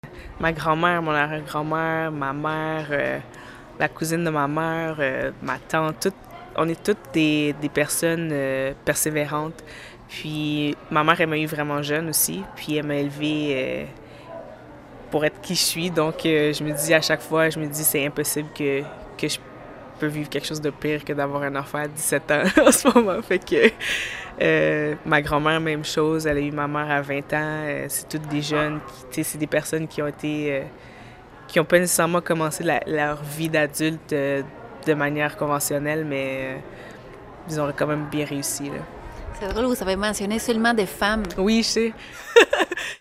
Entrevue et texte